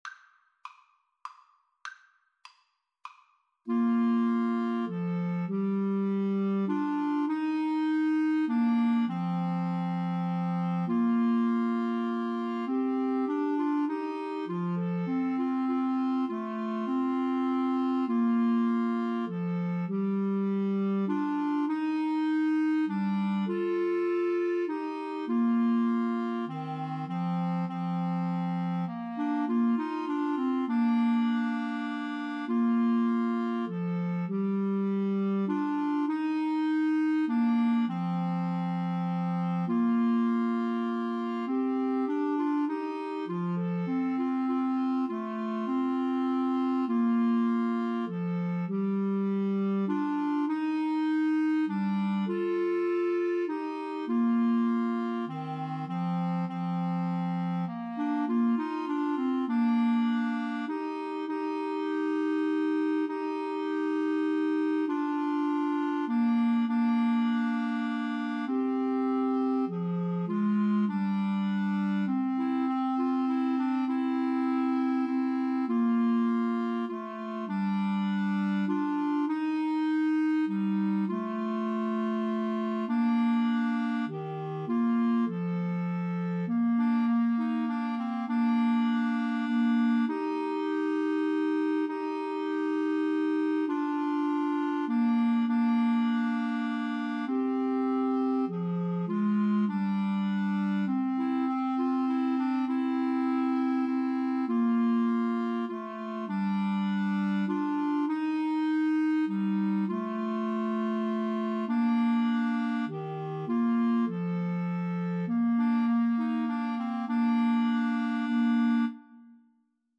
Bb major (Sounding Pitch) C major (Clarinet in Bb) (View more Bb major Music for Clarinet Trio )
3/4 (View more 3/4 Music)
Clarinet Trio  (View more Intermediate Clarinet Trio Music)
Classical (View more Classical Clarinet Trio Music)